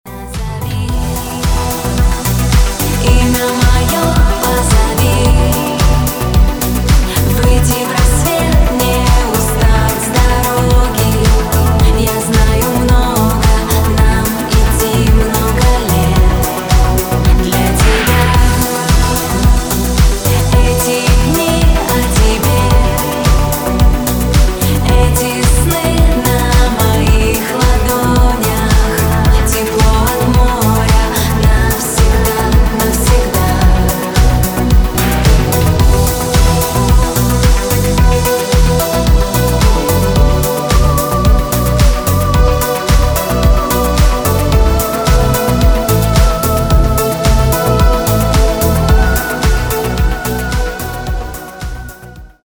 женский вокал
deep house
мелодичные
Cover